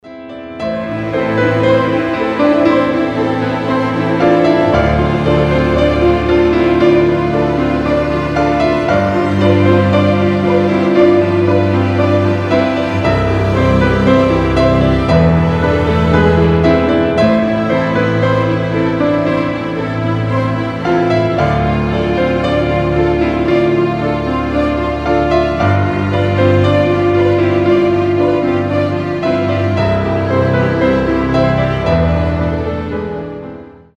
• Качество: 320, Stereo
без слов
красивая мелодия
скрипка
пианино
оркестр
Neoclassical
Современная классика